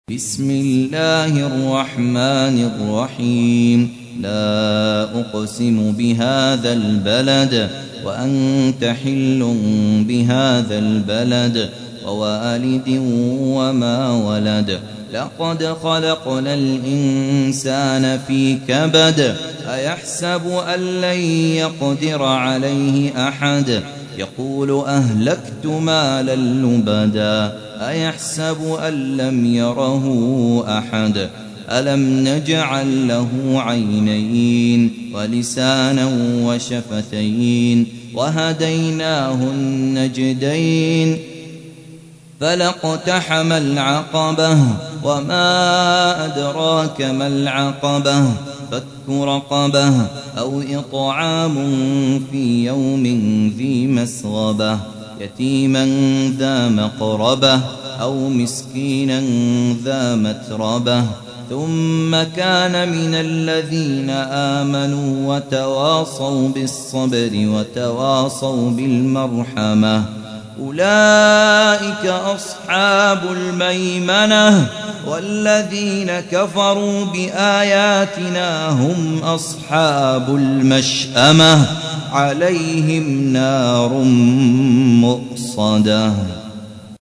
تحميل : 90. سورة البلد / القارئ خالد عبد الكافي / القرآن الكريم / موقع يا حسين